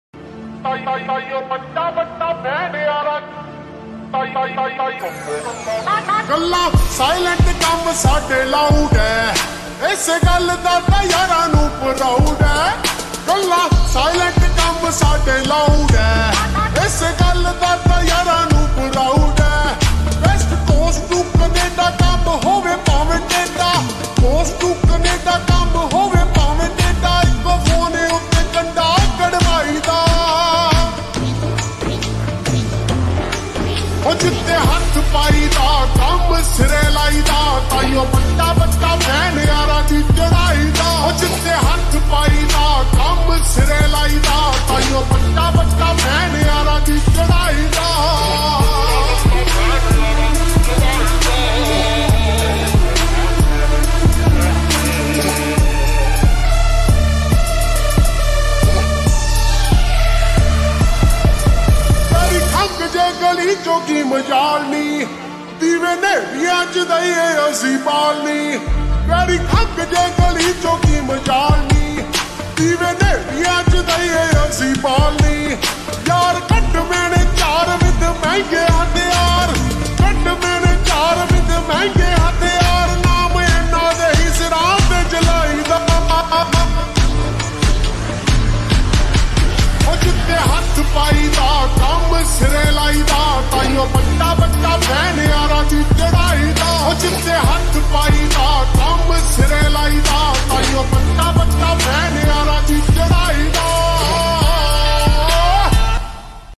Full song slow x reverb